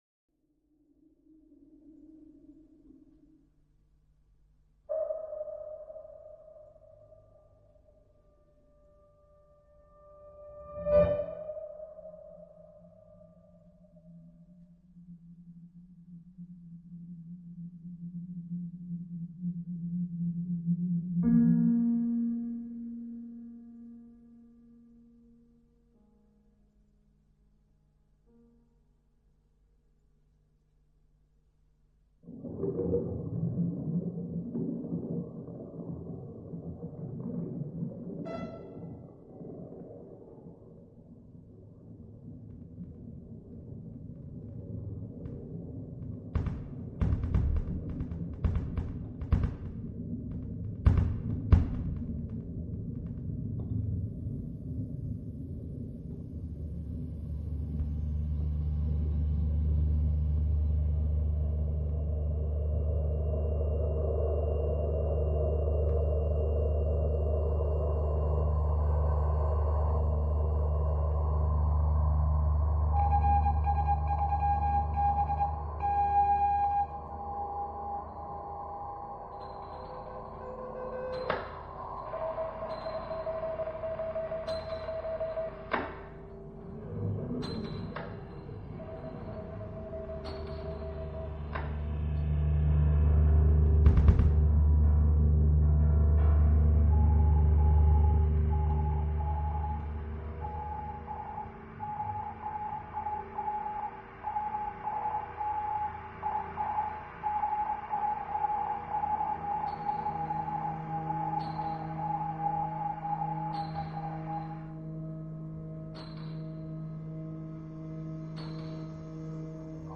sounding line Elektro-akustische Improvisation - 20 min.
Das Signal wird am anderen Ende mit einem Kontaktmikrophon abgenommen, verst�rkt und in die Komposition eingeflochten.
Sonar Pings, Schiffsmotoren und Nebelh�rner Kl�nge aus dem Bereich Meer und Schifffahrt bilden das environment durch das sie mit dem 12 min�tigen Improvisationsst�ck navigieren.
Echolot- und Klavierkl�nge haben einen �hnlichen physikalischen Aufbau: Ein klarer Anfangsimpuls ebbt rasch ab und verklingt. Diese Gemeinsamkeit wird durch das St�ck hindurch ausgelotet: Mal werden sie einander gegen�ber gestellt, mal scheinen sie einander zu imitieren, mal verschmelzen sie zu einem einzigen Klang. sound excerpt : sounding line